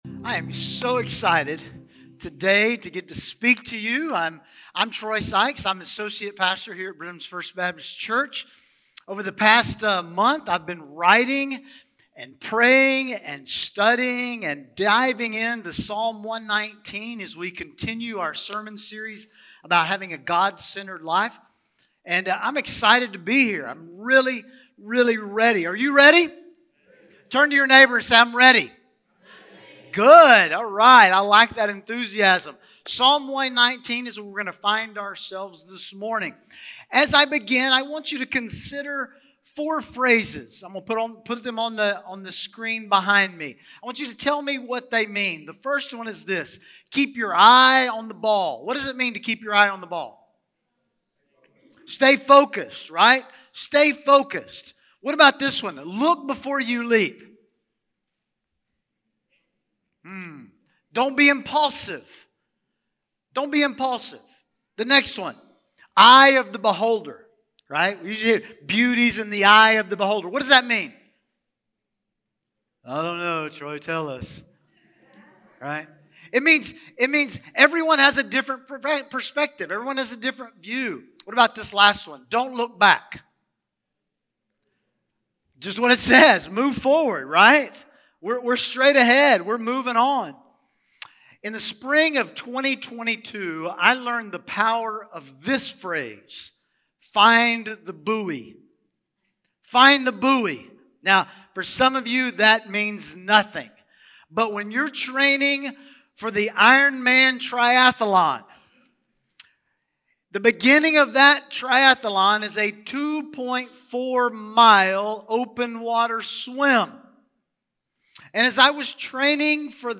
Sermons | First Baptist Church, Brenham, Texas